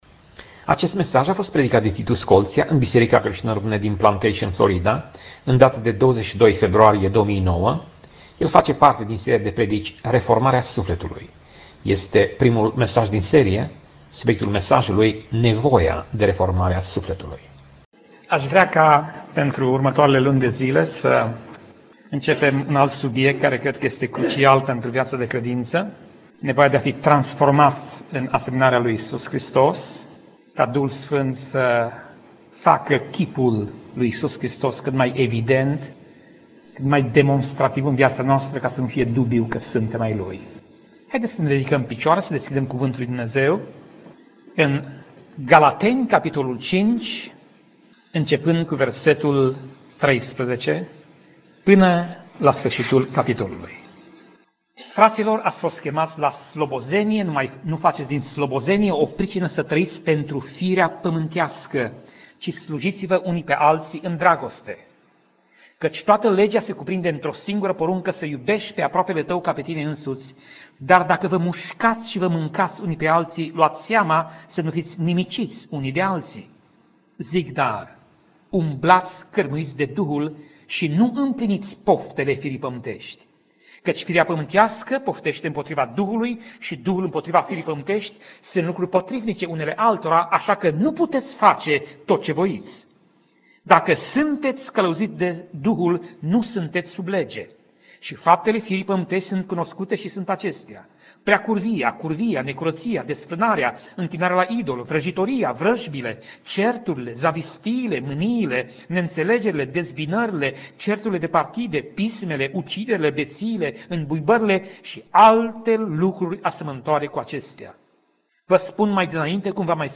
Pasaj Biblie: Galateni 5:16 - Galateni 5:26 Tip Mesaj: Predica